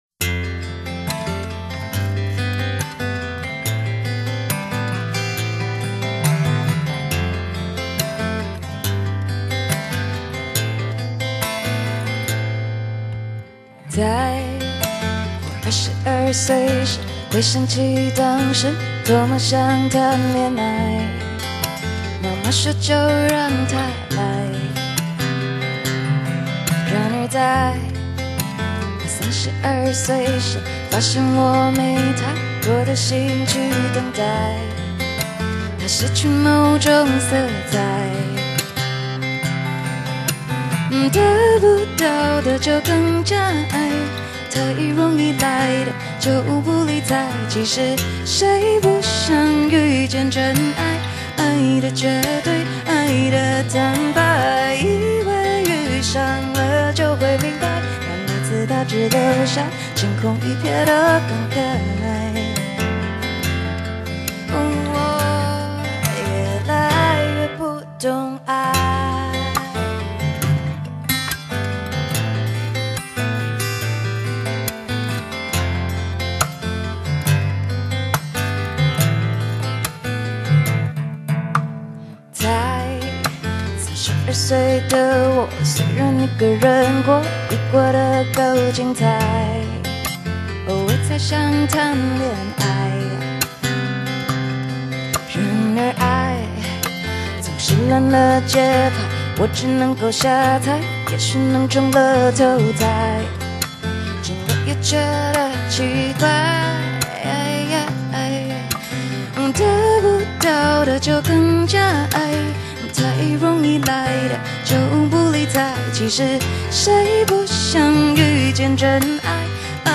藉由純淨的吉他 與誠摯的歌聲